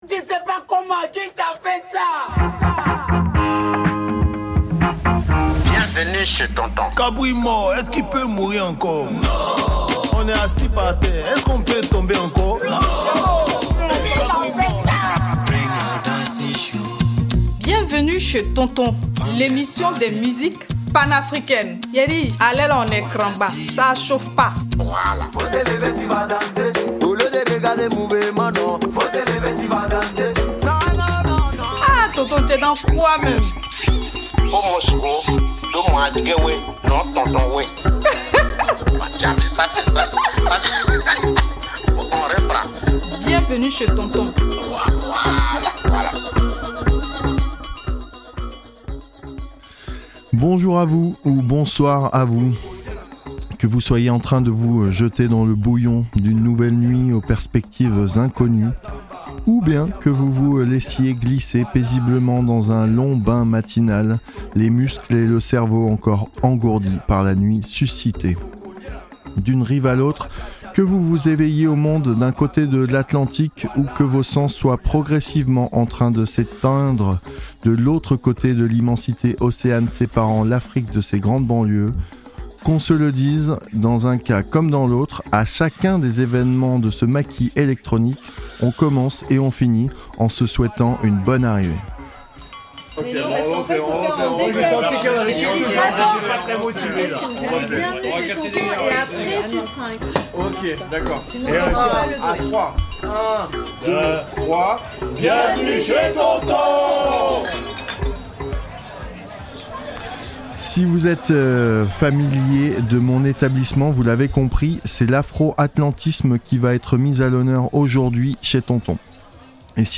Surtout des musiques africaines contemporaines mais sans beaucoup de contrainte, tant que cela retransmet mon amour pour les musiques issues d'Afrique ou de la diaspora.